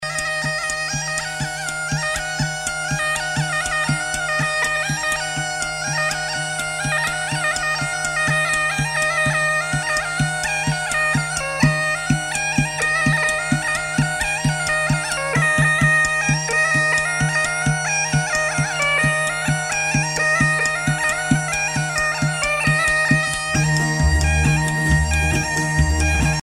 danse de Gyimes
Pièce musicale éditée